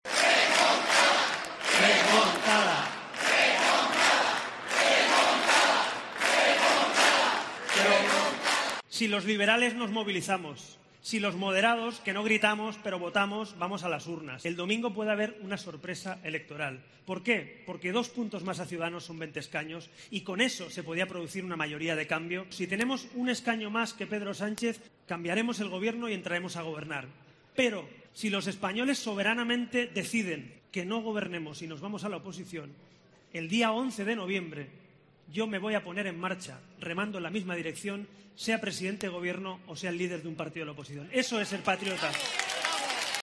"El domingo tenemos la ocasión de decirle a Sánchez que no nos hemos equivocado votando porque si vamos a votar, está claro que esta vez te vamos a echar y mandar a la oposición", ha asegurado Rivera en un acto en el Palacio de Congresos, el más multitudinario de toda la campaña naranja.